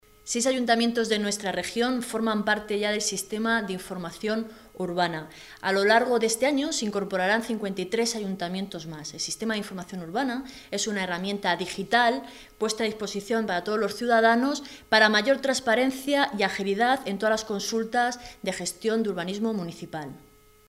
Marta Abarca, diputada regional del PSOE de Castilla-La Mancha
Cortes de audio de la rueda de prensa